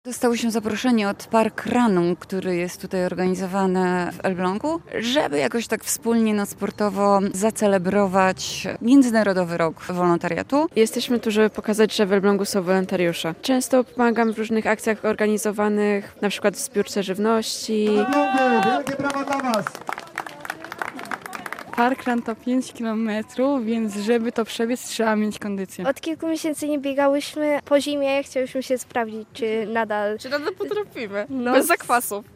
Posłuchaj relacji osób biorących udział w biegu zwanym parkrun: